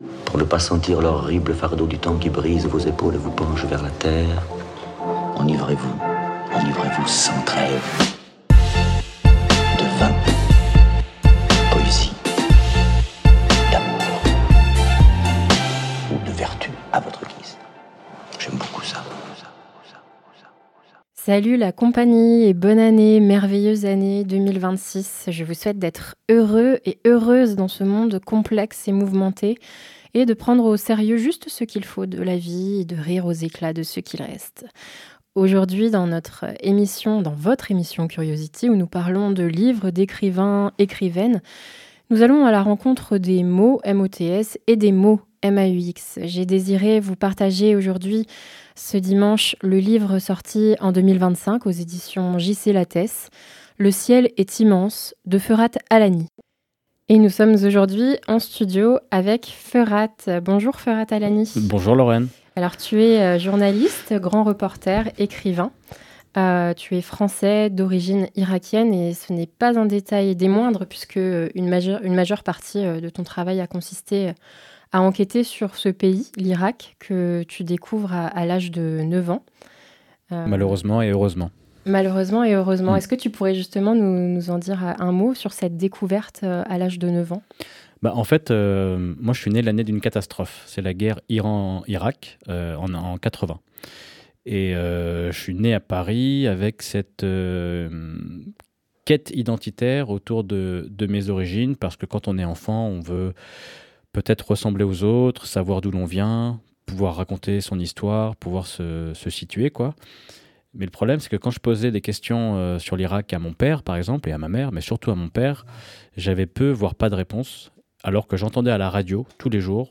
Enfin, nous parlerons avec Feurat de l’Irak d’hier et d’aujourd’hui, ainsi que de l’actualité internationale, au cœur de son travail de grand reporter.